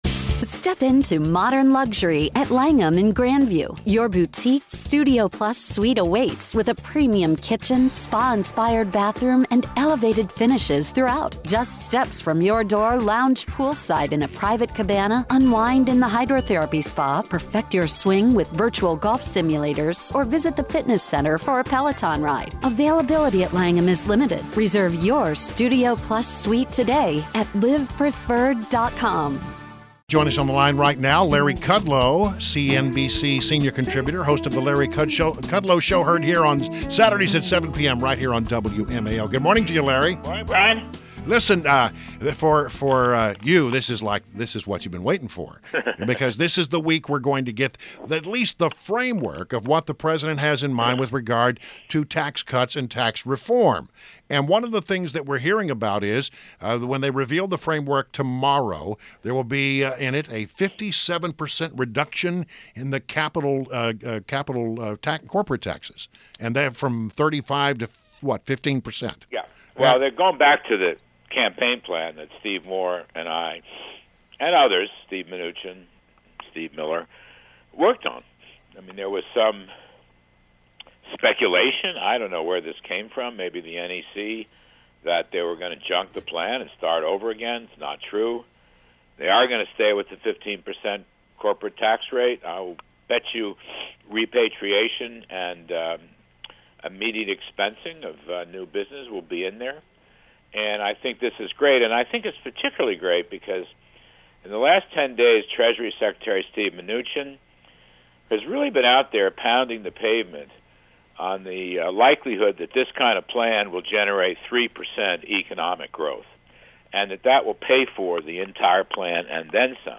WMAL Interview - LARRY KUDLOW - 04.25.17